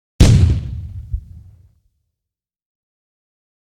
Explosion - Copy.wav